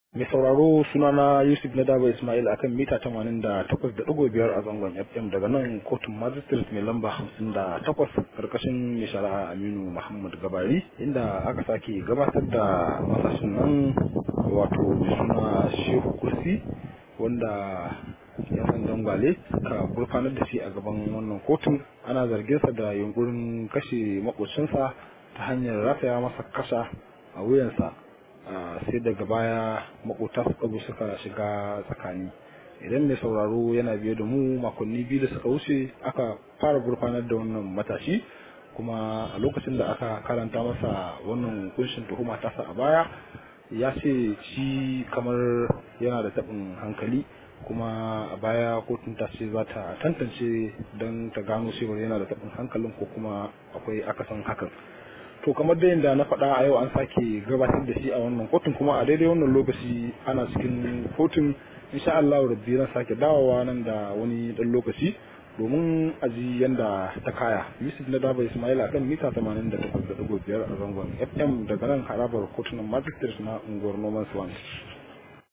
Rahoto: Matashin da ake zargi zai kashe makwabcin sa ya sake gurfana a kotu